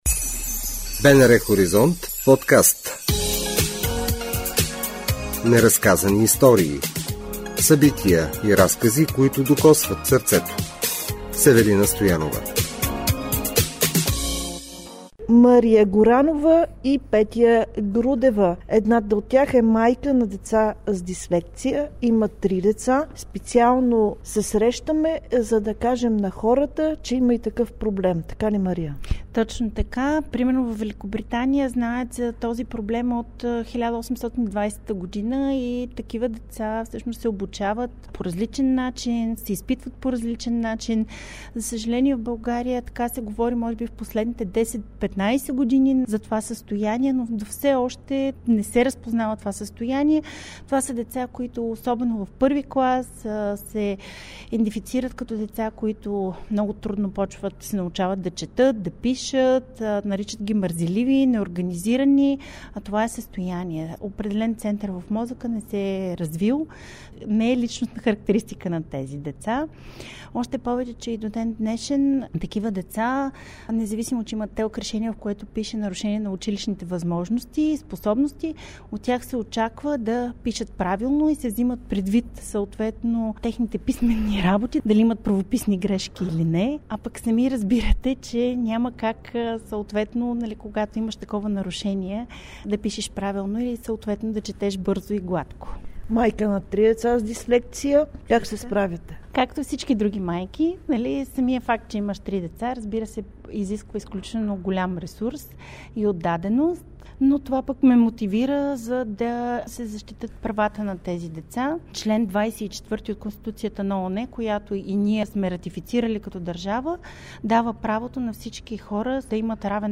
В този брой на Неразказани истории на фокус са проблемите на децата с дислексия – говорят родителите, като изтъкват...